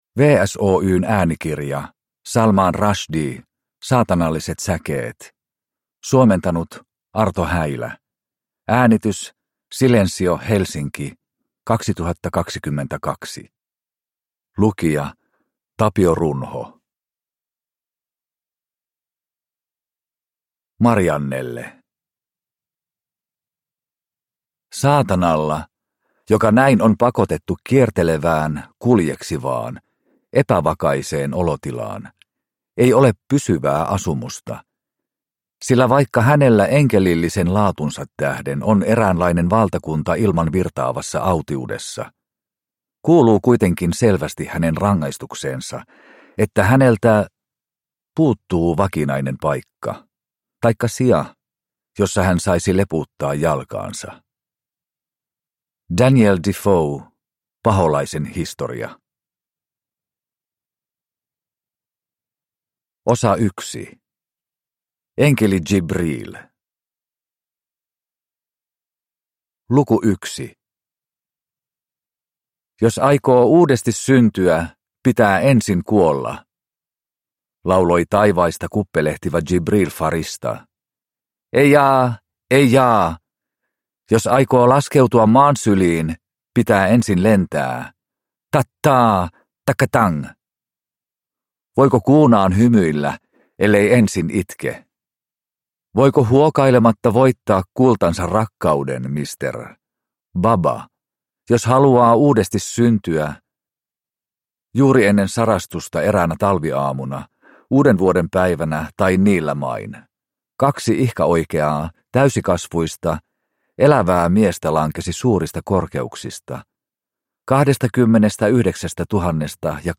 Saatanalliset säkeet (ljudbok) av Salman Rushdie